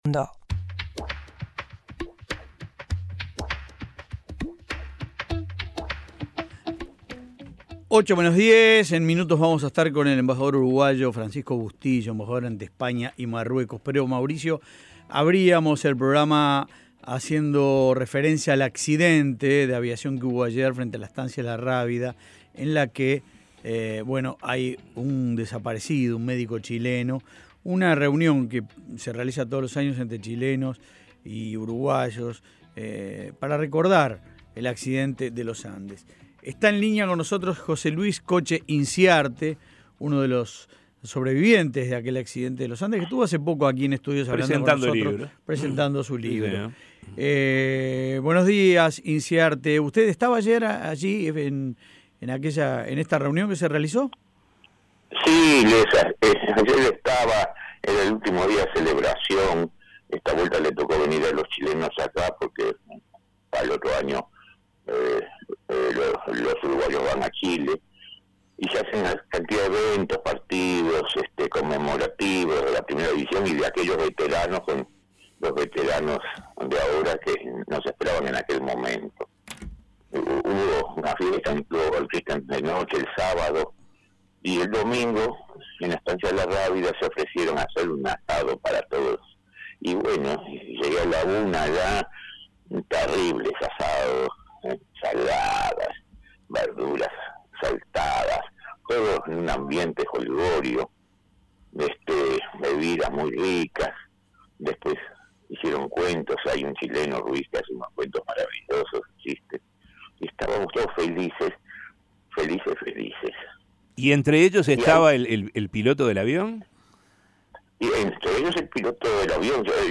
Estremecedor testimonio de Coche Inciarte sobre caída del avión este domingo